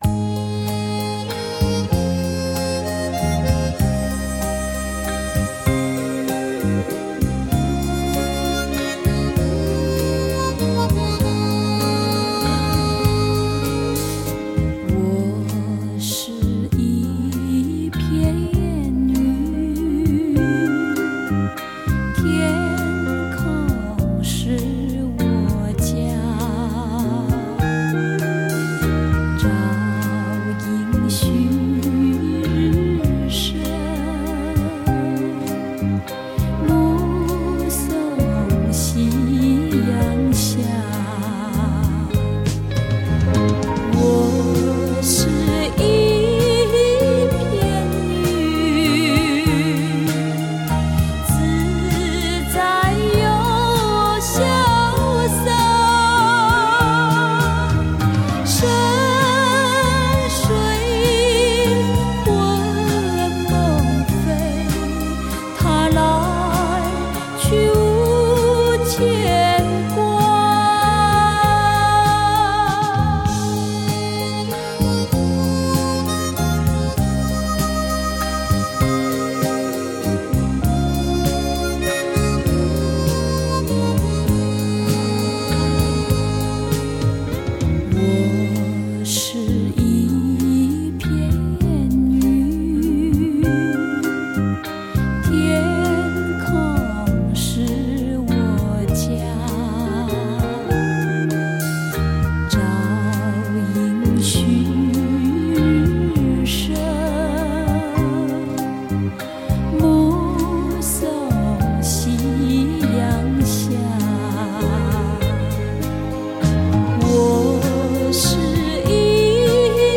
1979年 重新编曲·录音版